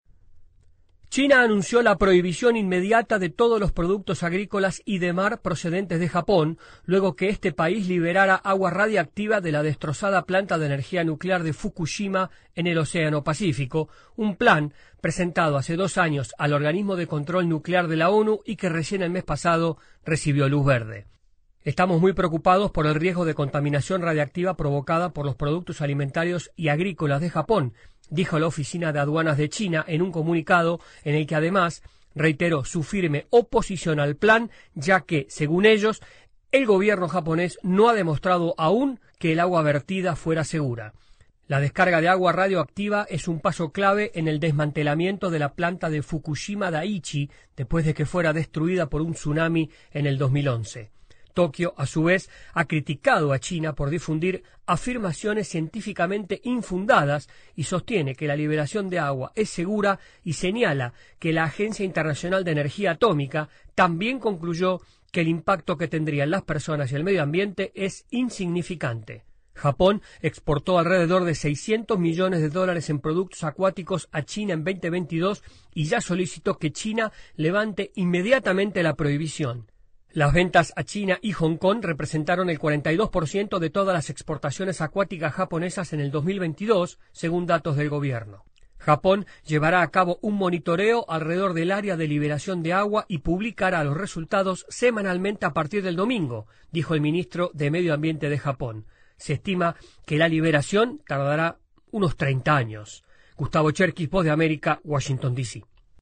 AudioNoticias